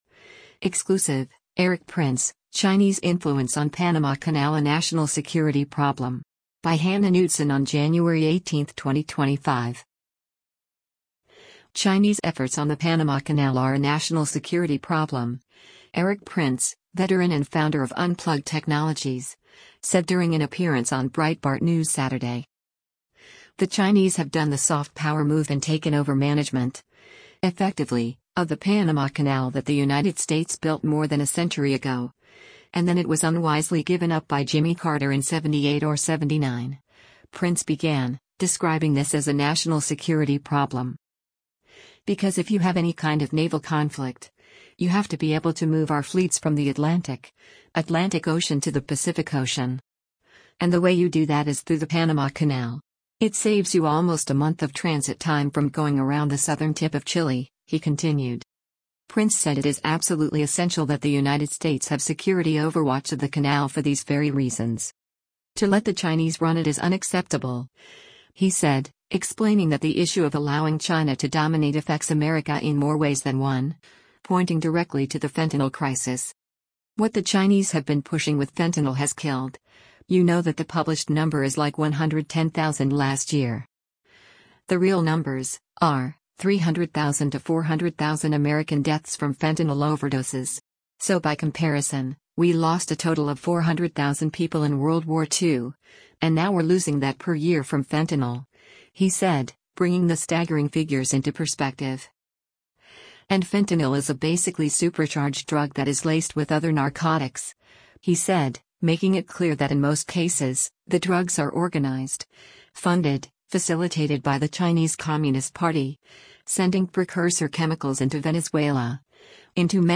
Chinese efforts on the Panama Canal are a “national security problem,” Erik Prince, veteran and founder of Unplugged Technologies, said during an appearance on Breitbart News Saturday.